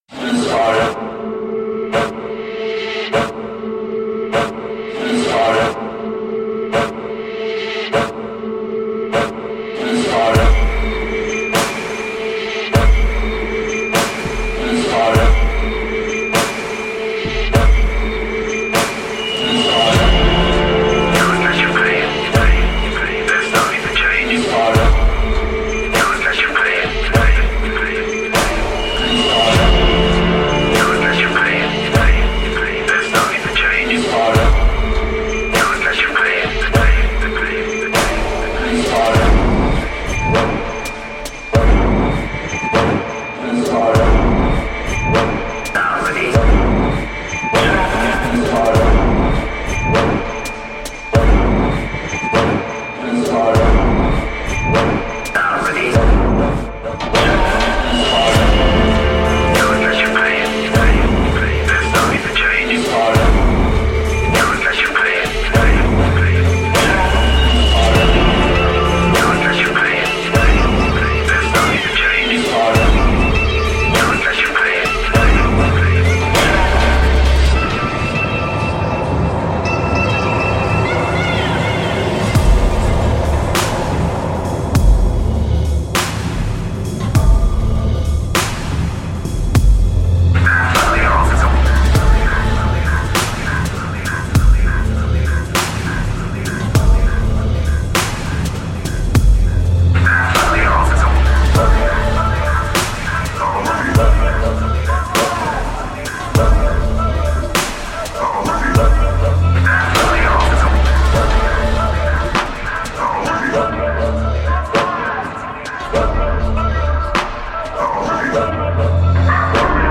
We tried to make order out of chaos - to add space to something very crowded. " Part of our project The Next Station, reimagining the sounds of the London Underground and creating the first ever tube sound map.